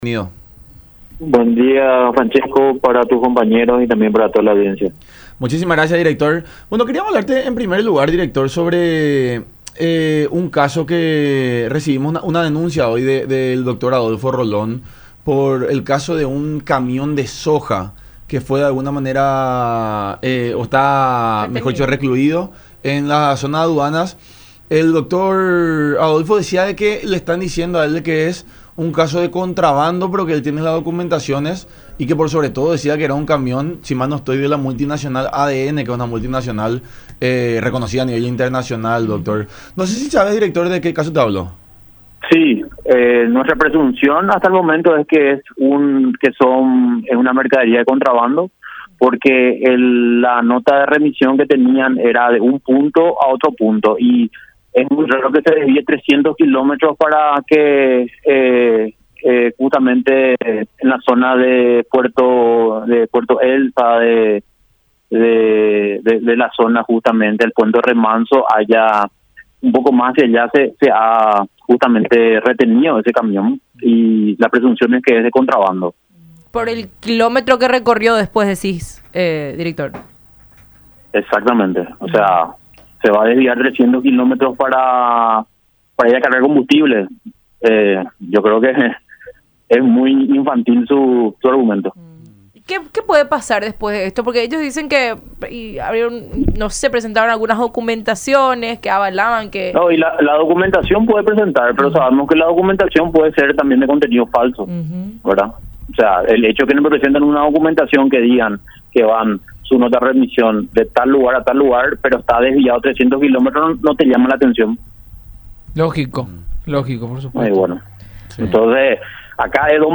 “Argumentan una supuesta subvaloración del producto, por eso ordenaron la retención de los camiones, son 18 los que están en puerto Pilcomayo y una 6 más que están retenida en Argentina”, agregó en el programa “La Unión Hace La Fuerza” por radio La Unión Hace La Fuerza” por radio La Unión.